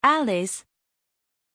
Pronunția numelui Alice
pronunciation-alice-zh.mp3